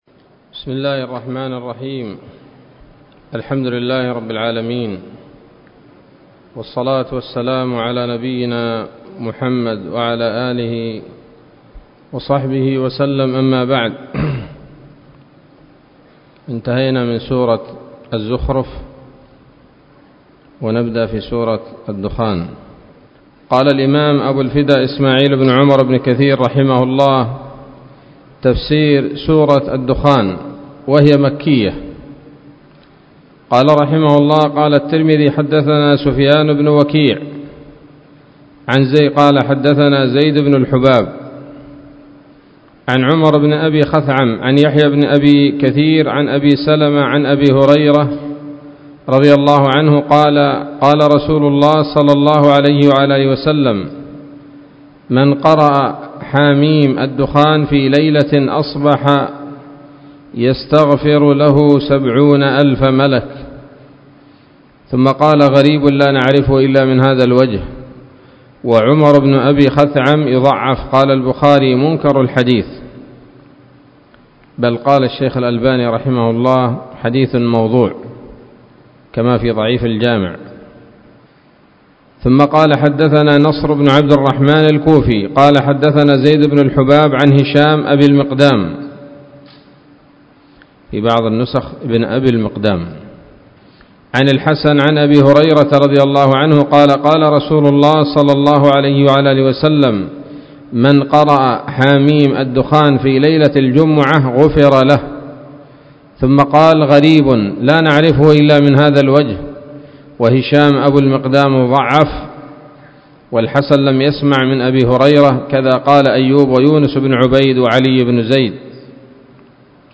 الدرس الأول من سورة الدخان من تفسير ابن كثير رحمه الله تعالى